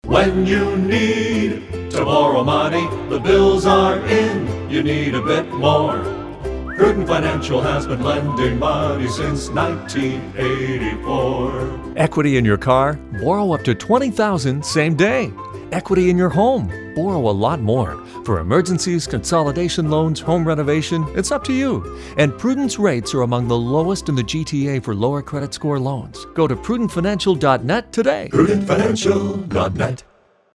We have three spots playing on 680 News, Toronto’s all-news radio.